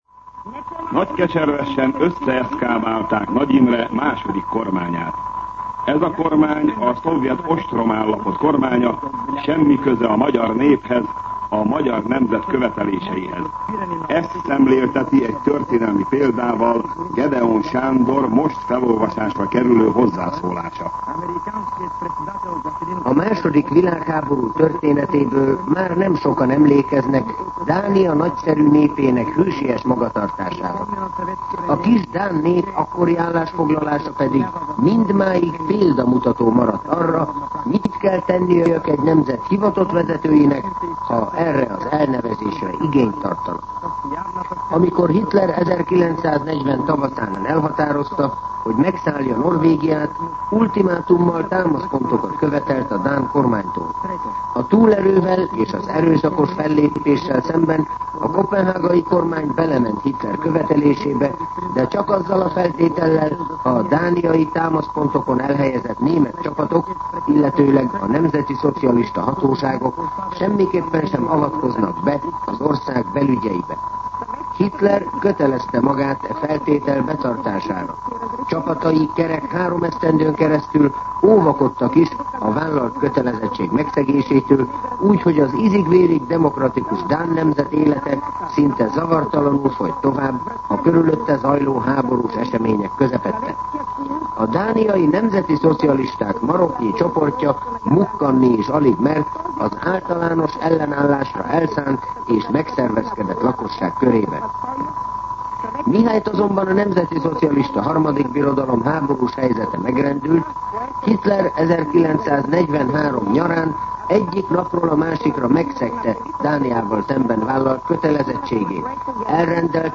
MűsorkategóriaKommentár